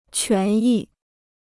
权益 (quán yì): rights; interests.